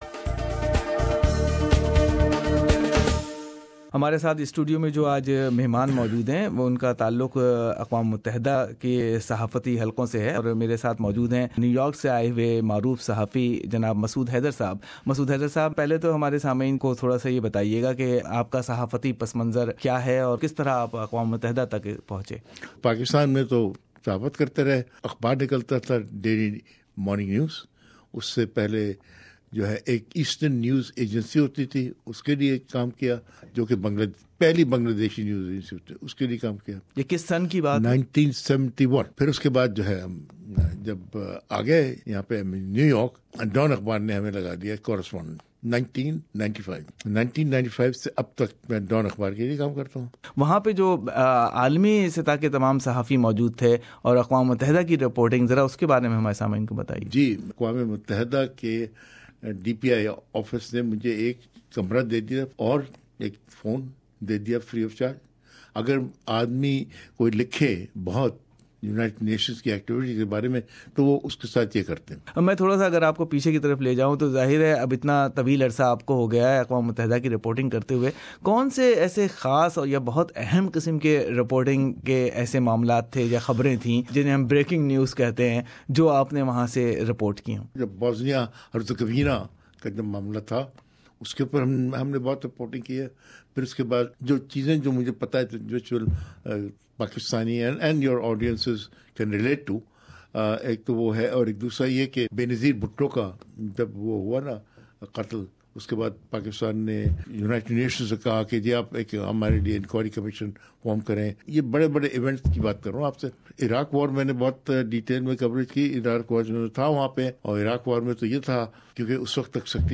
UN to US: Analysis by a New York Journalist